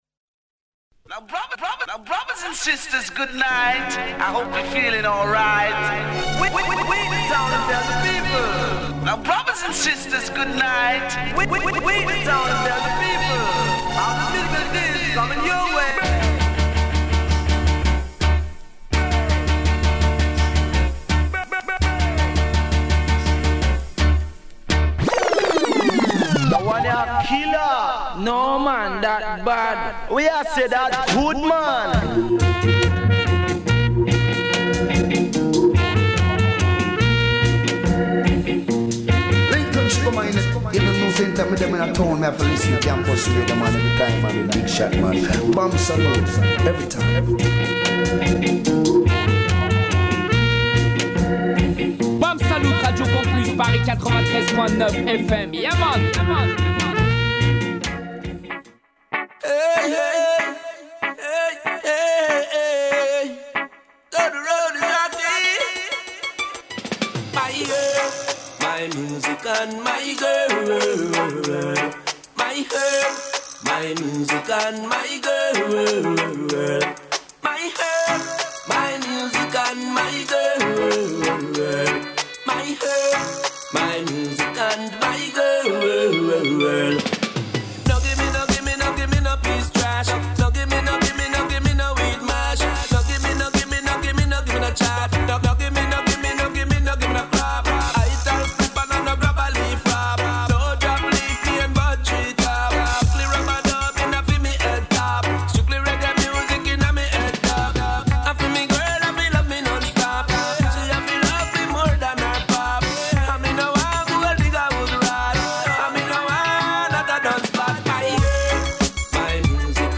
par séries alternées de 5 tunes